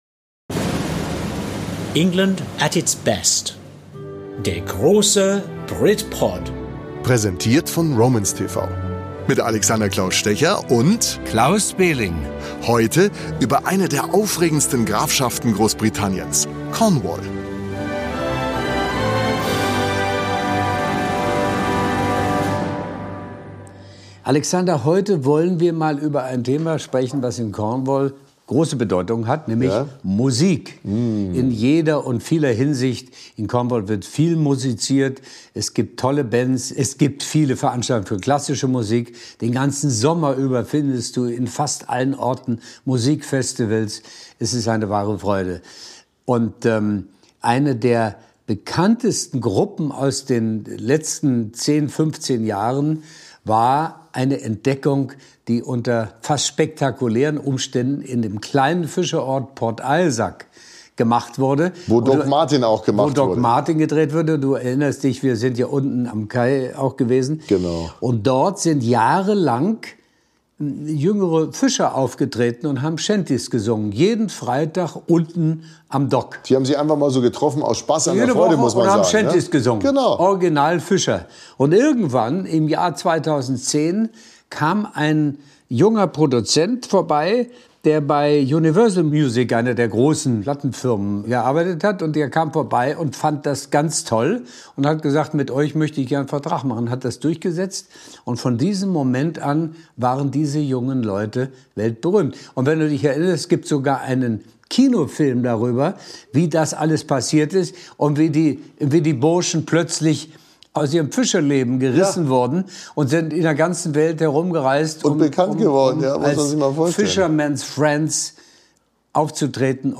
Ehrensache, dass er sich hier im Podcast an den Flügel setzt und einige seiner emotionalen Melodien zum Besten gibt.